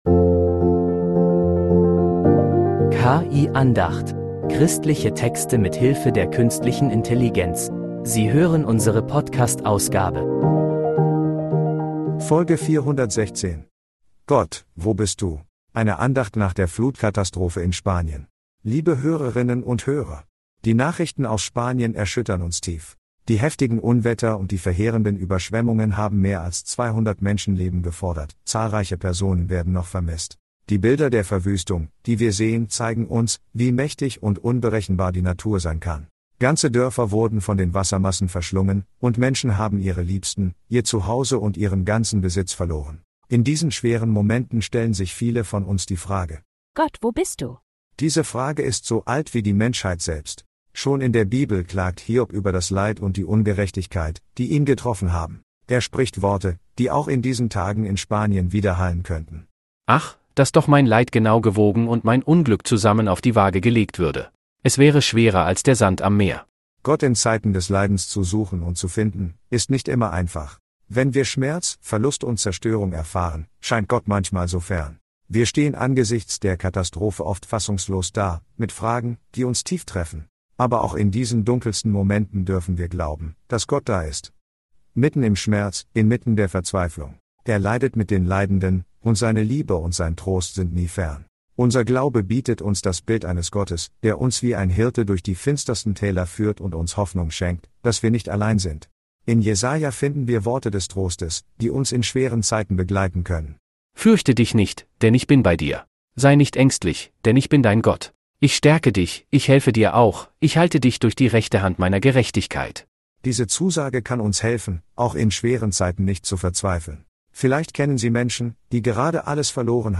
Eine Andacht nach der Flutkatastrophe in Spanien